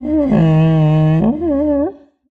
assets / minecraft / sounds / mob / sniffer / idle1.ogg